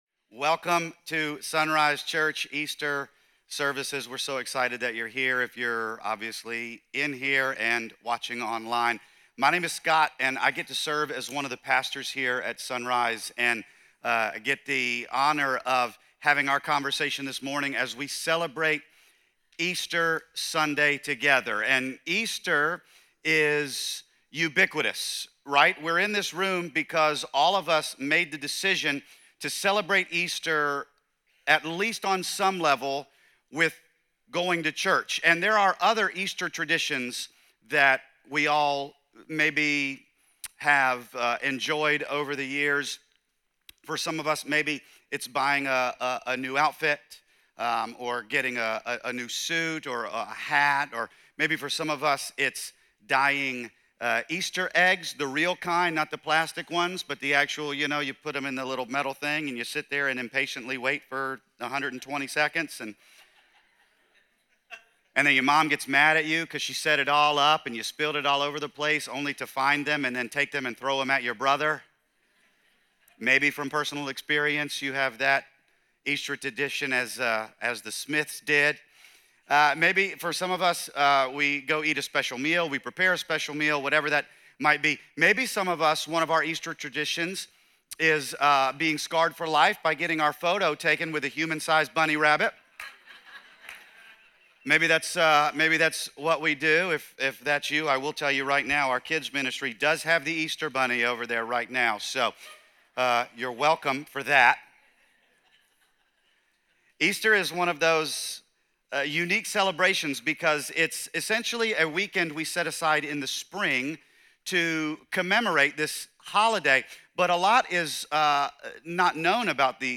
Through engaging storytelling, humor, and biblical truth, it challenges each listener to personally reflect on their response to God’s love.